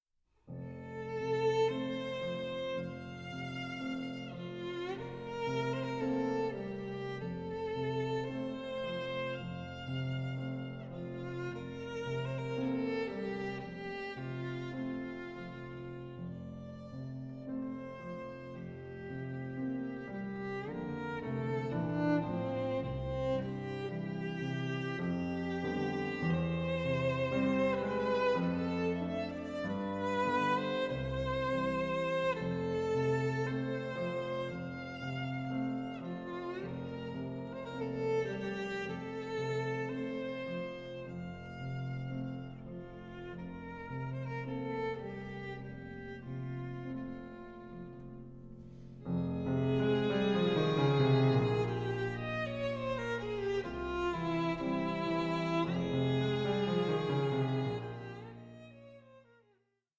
Langsam 3:14